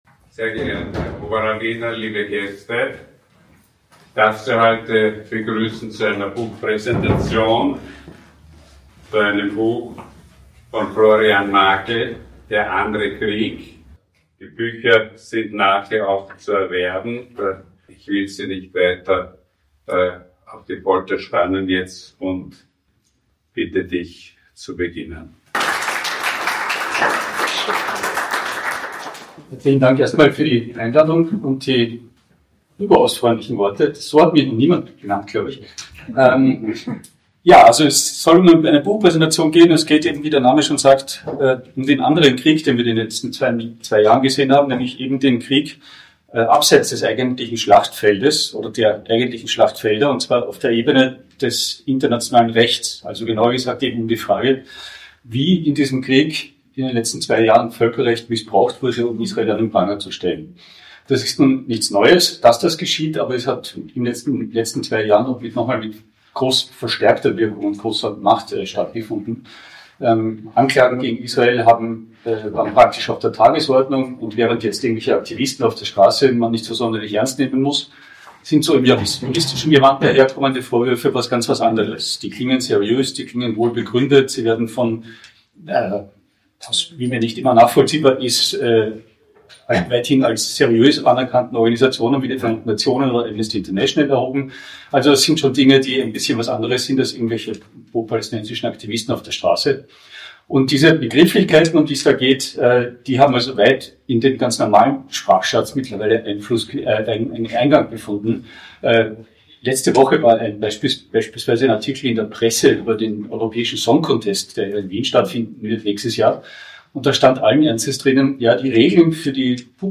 Wir präsentieren die Aufzeichung der Veranstaltung.